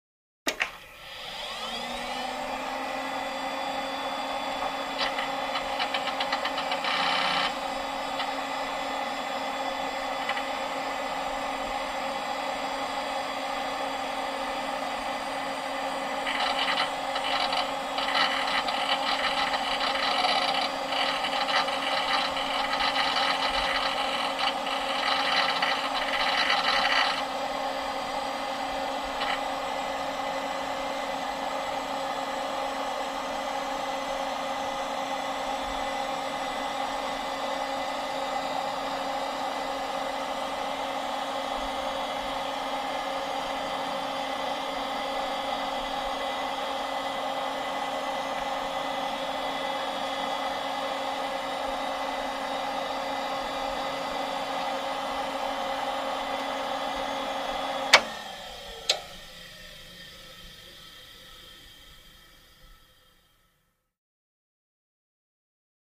Mac; On / Boot / Steady / Off; Desktop Computer; Power On / Hard Drive And Fan Spin Up / Hard Drive Access / Steady Fan Idle / Power Off / Hard Drive And Fan Spin Down, Close Perspective.